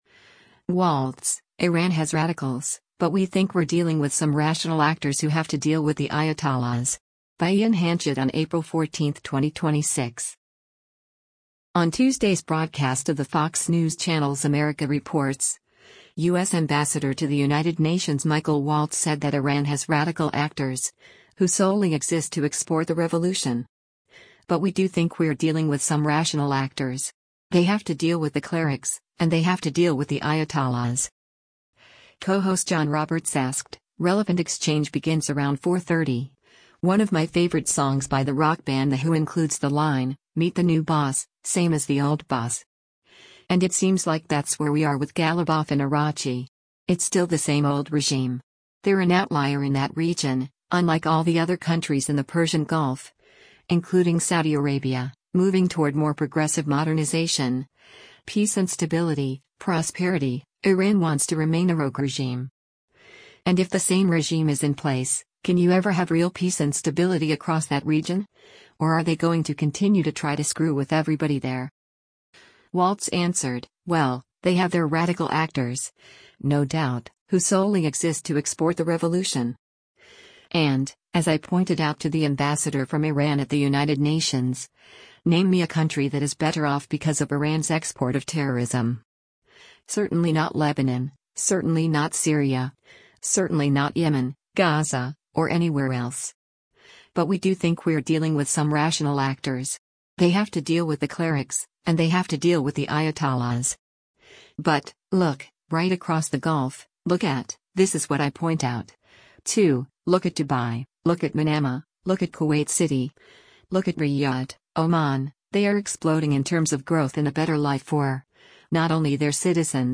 On Tuesday’s broadcast of the Fox News Channel’s “America Reports,” U.S. Ambassador to the United Nations Michael Waltz said that Iran has “radical actors…who solely exist to export the revolution.”